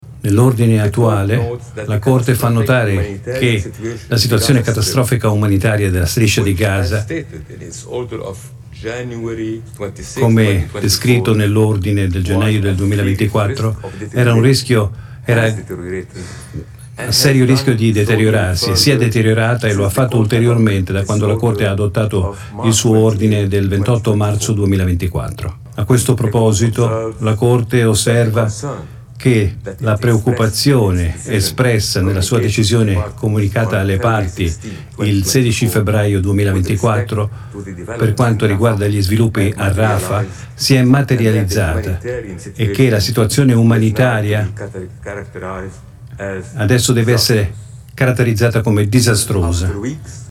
Sentiamo un passaggio del suo intervento: